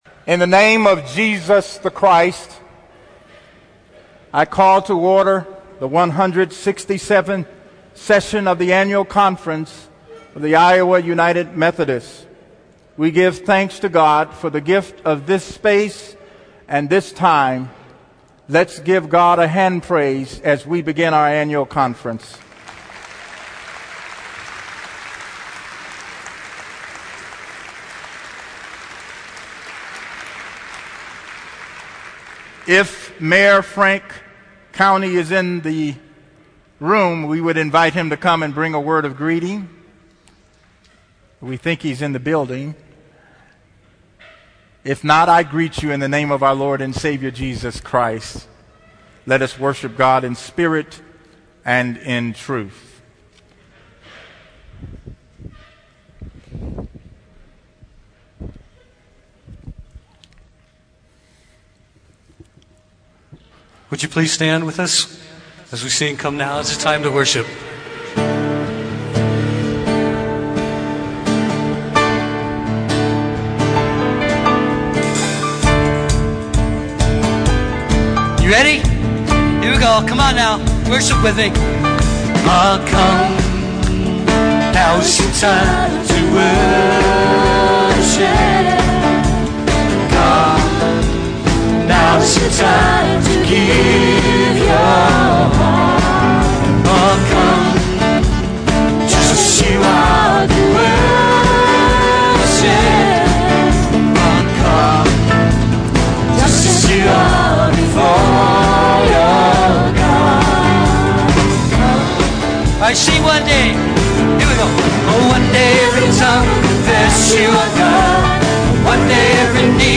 On Saturday, the Call to Order was 10:00 A.M. with a Worship Service.